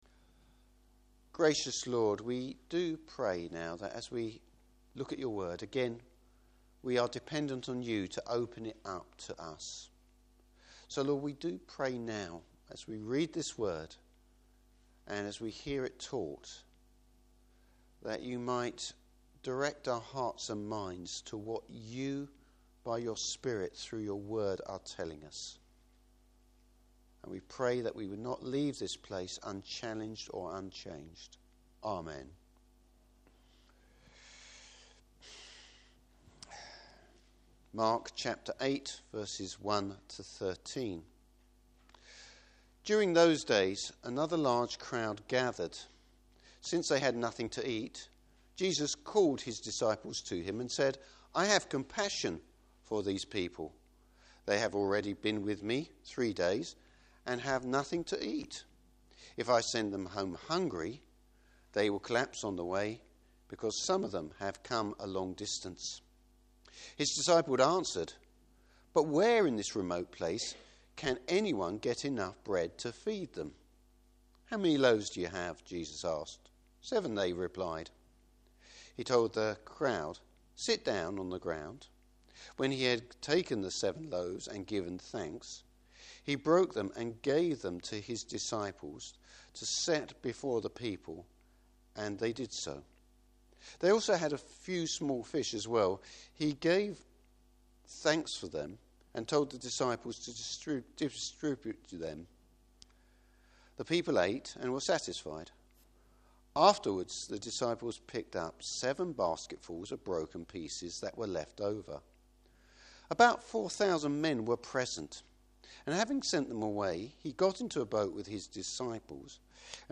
Service Type: Morning Service Another miracle.